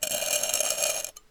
sounds_scrape_06.ogg